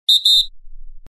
Referee Whistle Sound Button - Free Download & Play